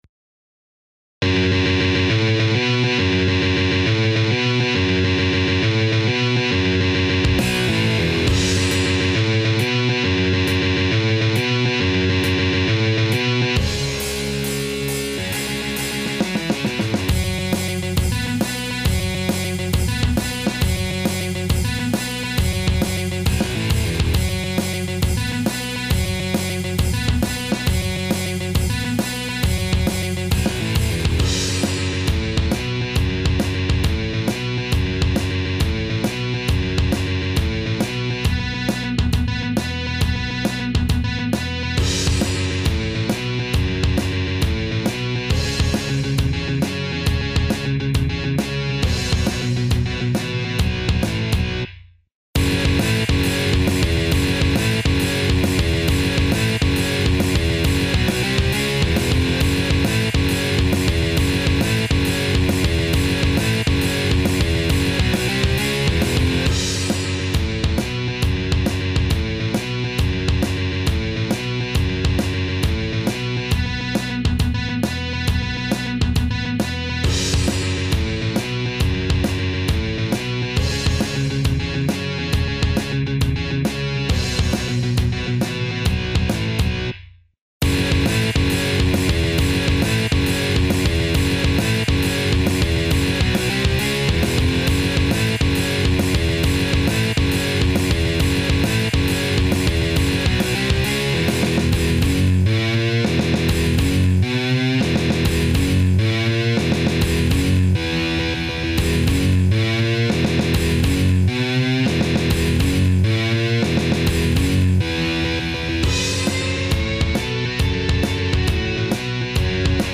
минусовка версия 229728